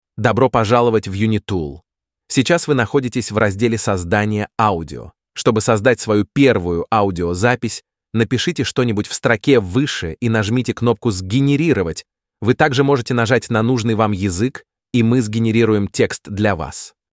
Convert text to realistic speech using the Eleven Labs AI neural network.